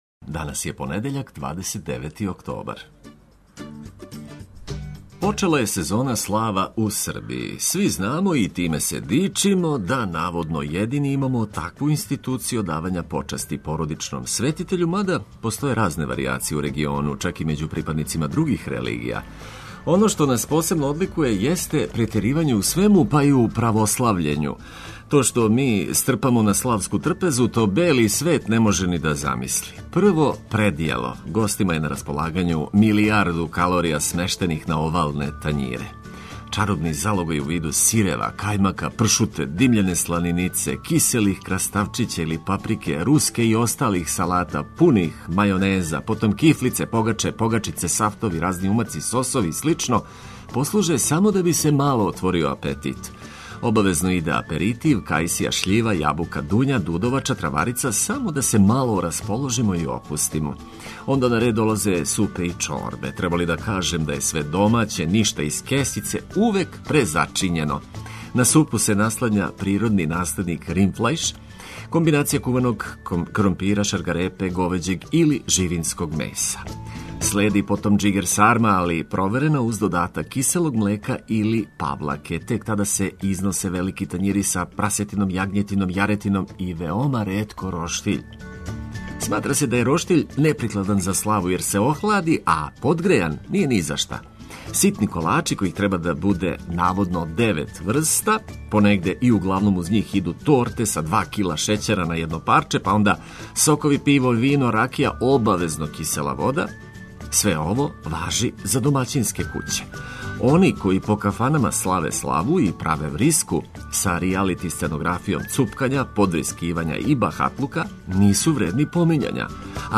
Водитељ: